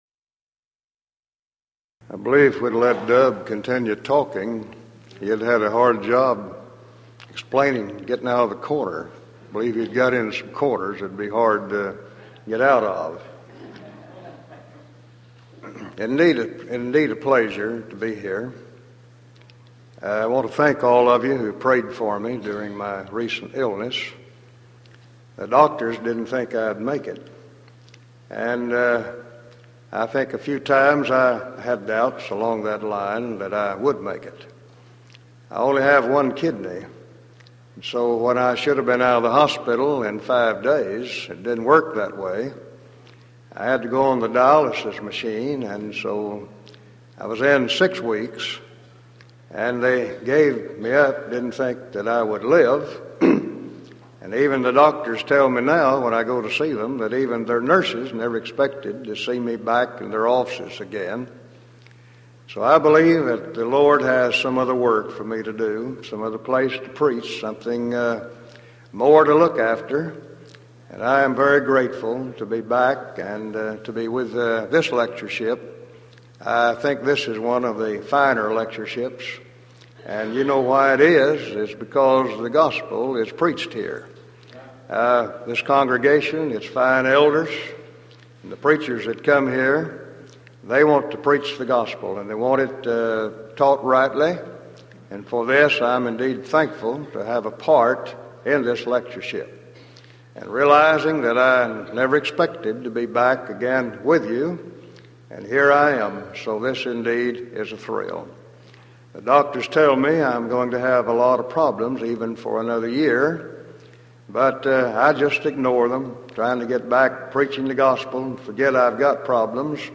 Series: Denton Lectures Event: 1993 Denton Lectures